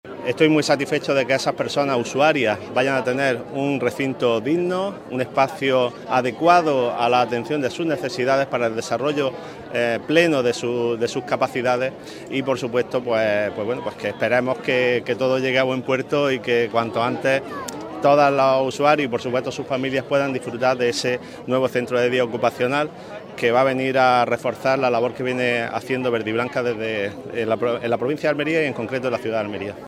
JOSE-MARIA-MARTIN-SUBDELEGADO-GOBIERNO-PRIMERA-PIEDRA-CENTRO-VERDIBLANCA.mp3